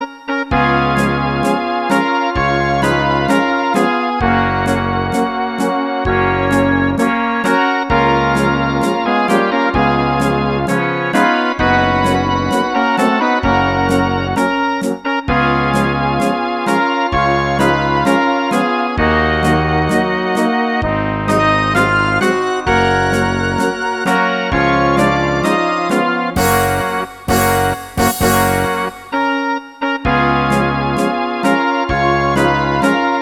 Rubrika: Národní, lidové, dechovka
- smuteční pochod